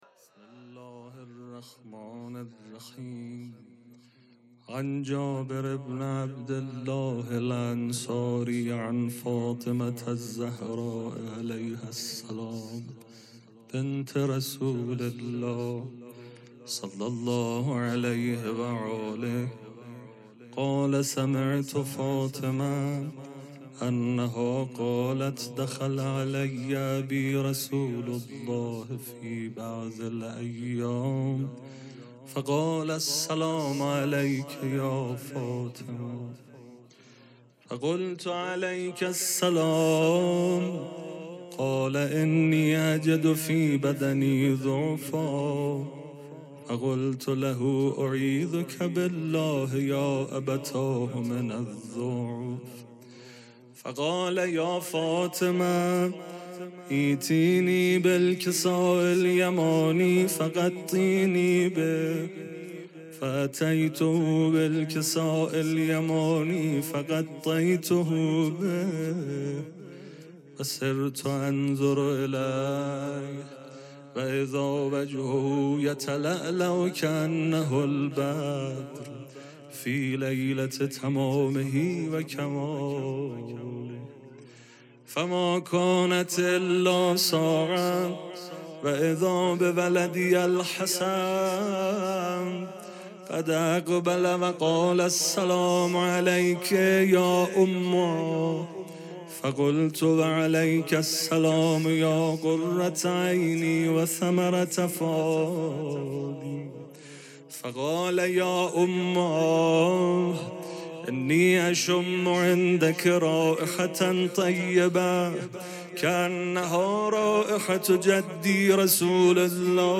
پیش منبر